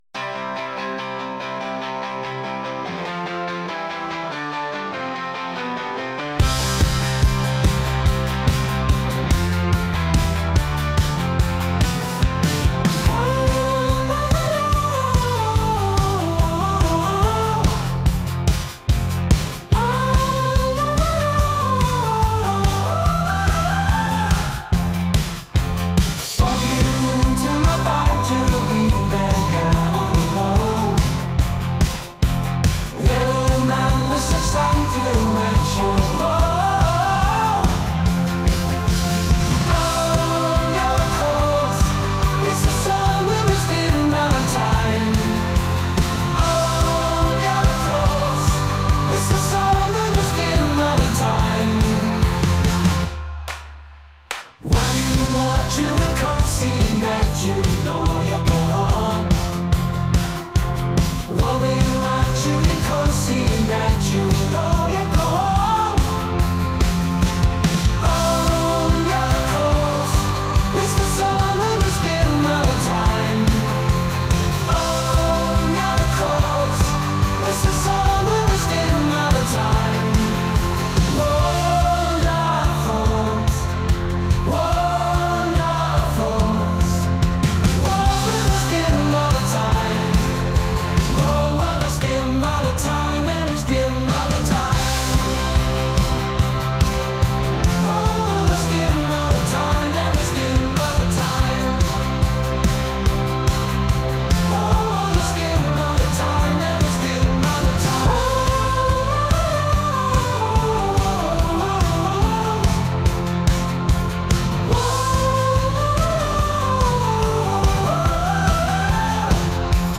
pop | indie | rock